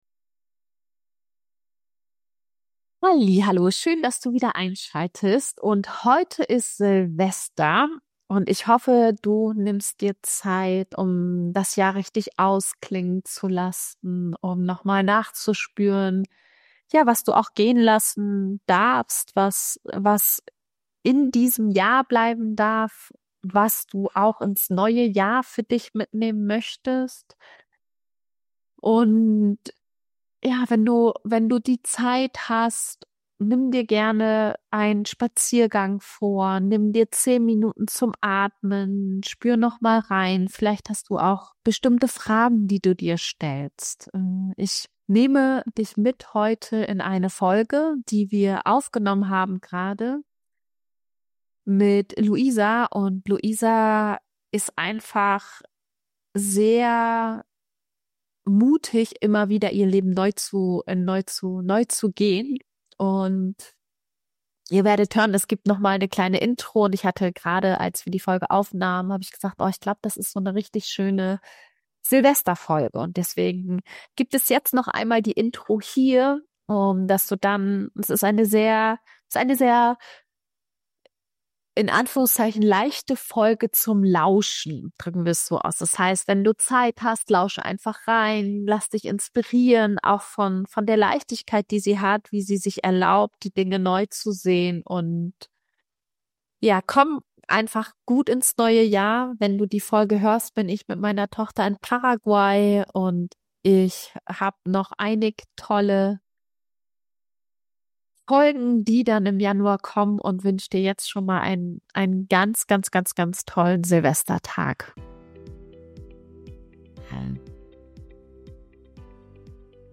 Wir sprechen darüber, wie sich ein Leben anfühlt, in dem du mehr als einmal alles auf „Neustart“ stellst – vom Dorfkind zur Flugbegleiterin auf der Langstrecke, von der festen Wohnung ins Vanlife und jetzt hinein in einen komplett neuen beruflichen Weg.